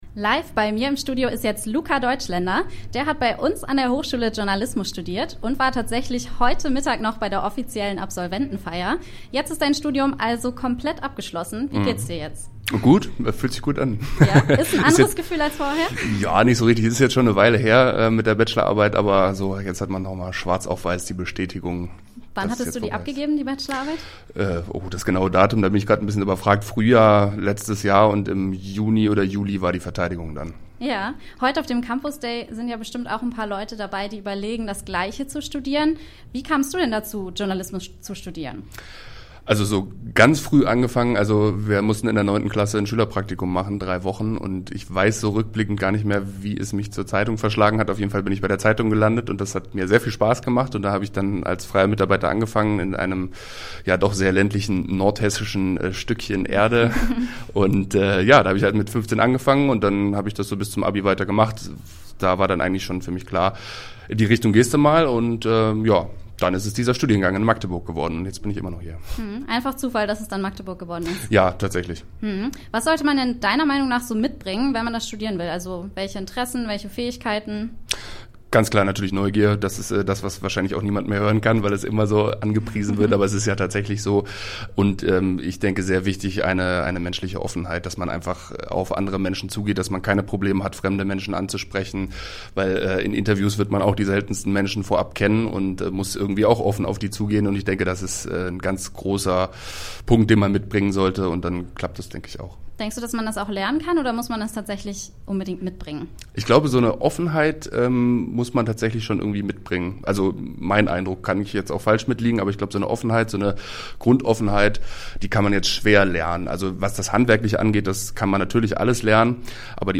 Das Interview vom Campusday 2018: